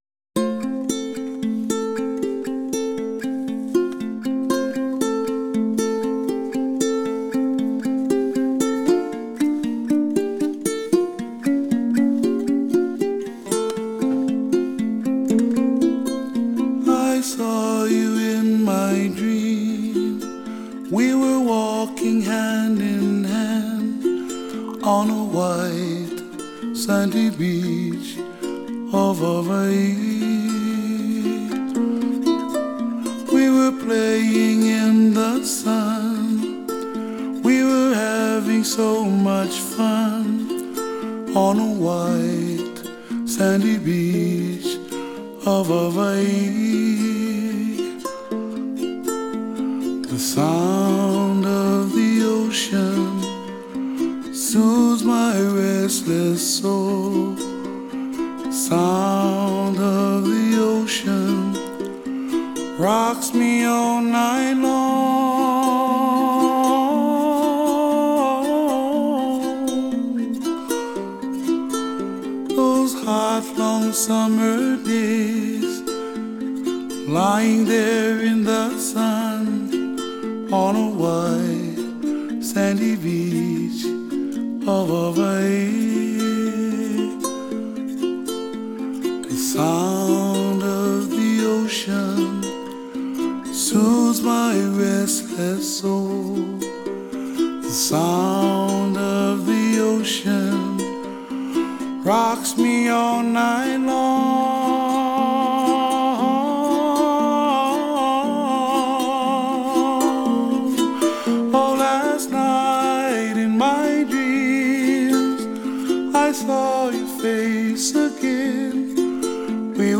How about a gentle tune about white sandy beaches?